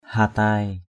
/ha-taɪ/